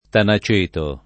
tanaceto